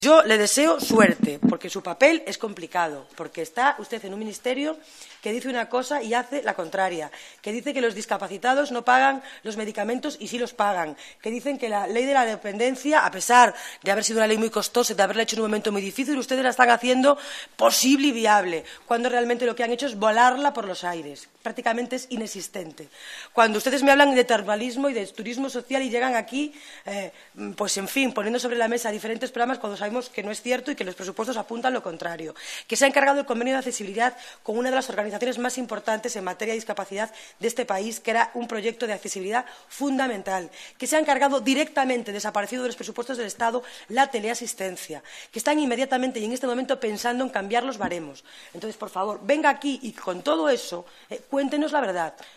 Laura Seara. Comisión de discapacidad 27/05/2014